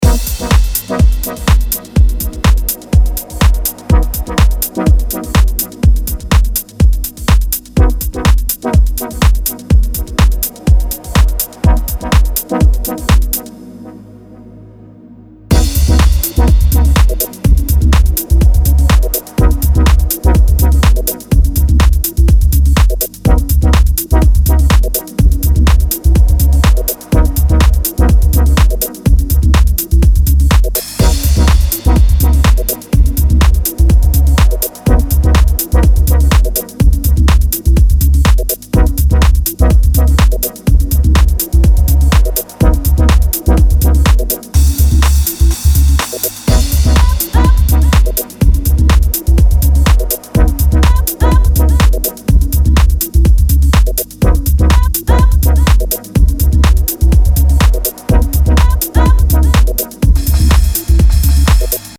• Deep House